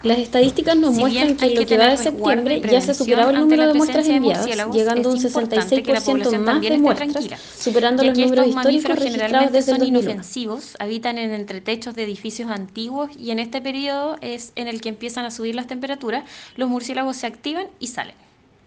La seremi de Salud, Cristina Ojeda, señaló que durante el año ya se superó el promedio histórico.